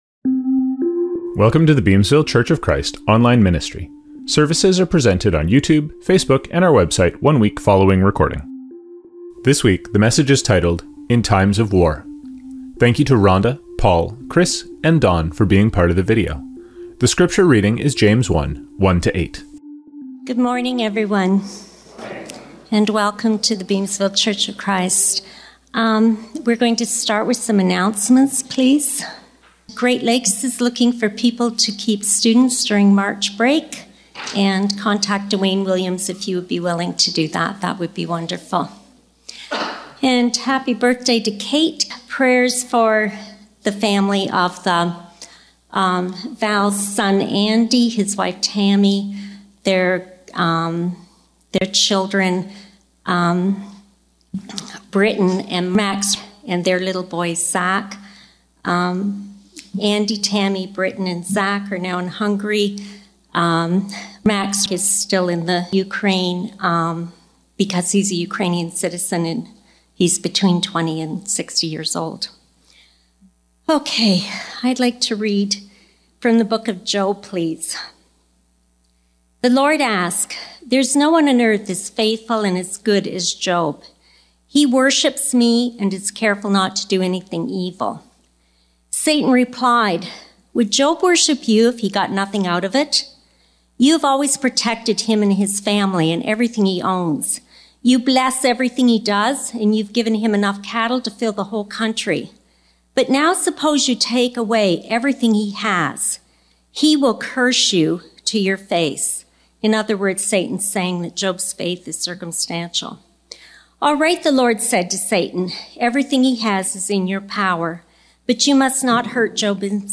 Songs from this service include